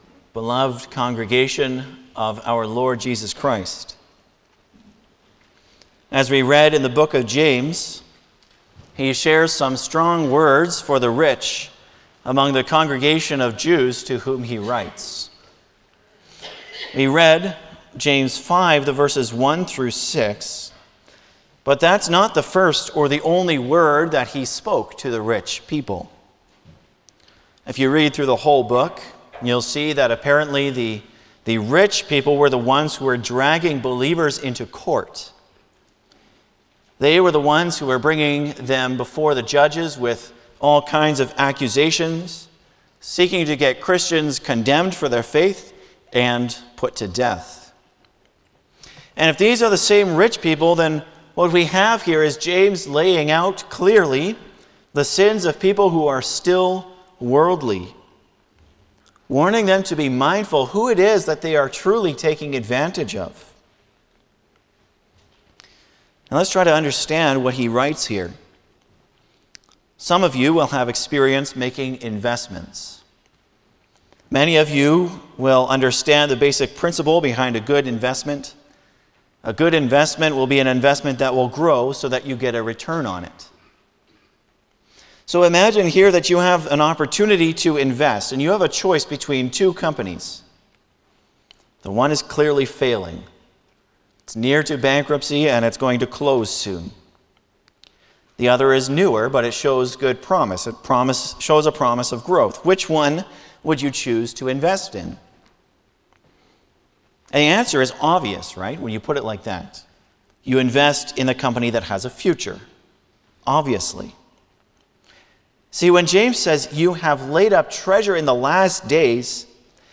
Passage: Lord’s Day 42 Service Type: Sunday afternoon
07-Sermon.mp3